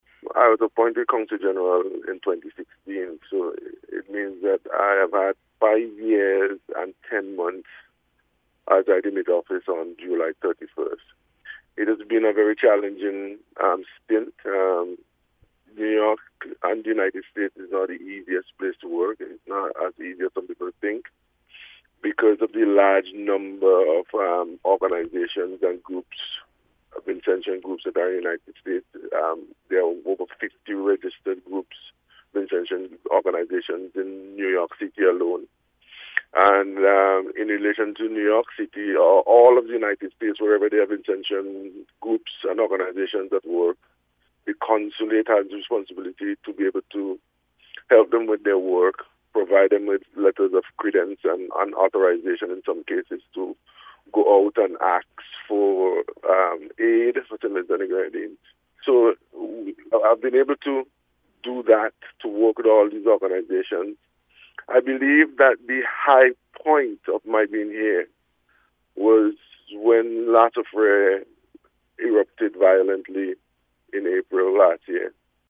Meanwhile, outgoing Consul General Howie Prince says despite some challenges, he was instrumental in implementing several projects, over the last five years.